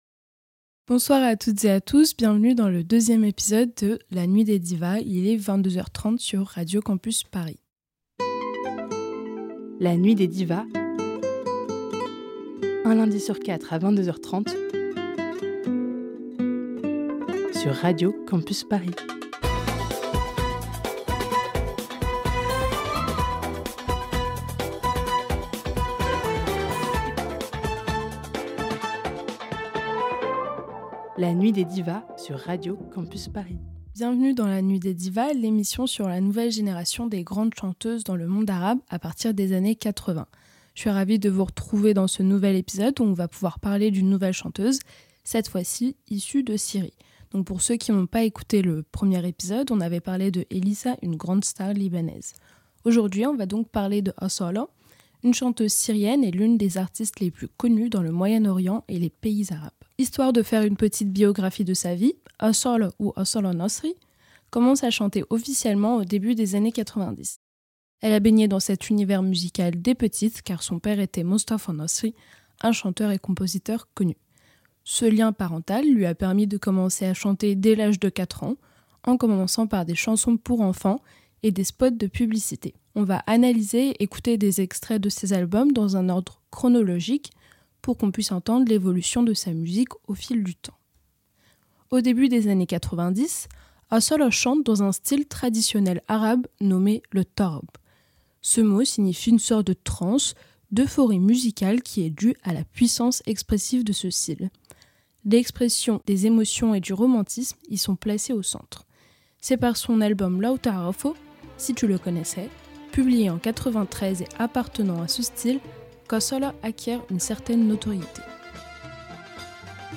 Le deuxième épisode de la nuit des Divas porte sur Assala, grande artiste syrienne. Entre style musical théâtral, ambivalence entre tradition musicale arabe héritée des divas classiques (Oum Kalthoum,…) et genres plus modernes, l’intensité et la puissance de sa voix, Assala s’impose comme une chanteuse incontournable de la scène musicale arabe contemporaine (mainstream).